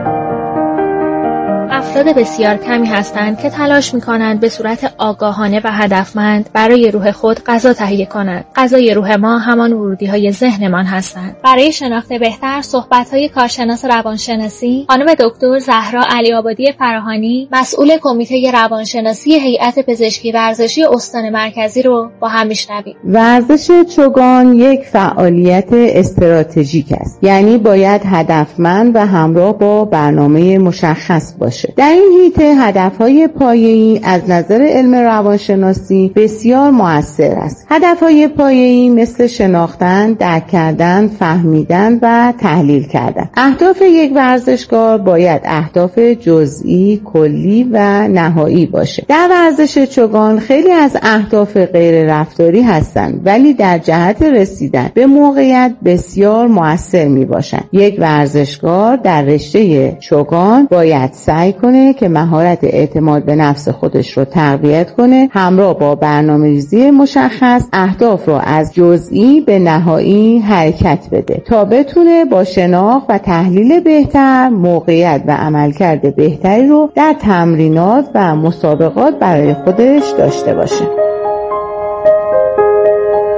گفتگوی رادیویی